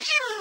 Minecraft / mob / cat / hit3.ogg
should be correct audio levels.